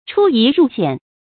出夷入險 注音： ㄔㄨ ㄧˊ ㄖㄨˋ ㄒㄧㄢˇ 讀音讀法： 意思解釋： 出入于安危之中。